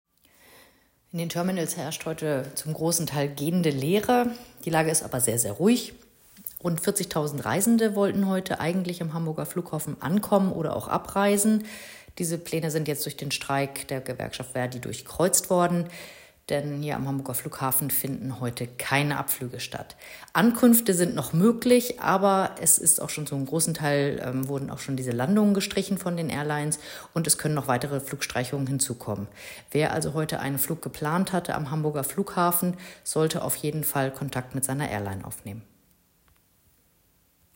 Audio-Statement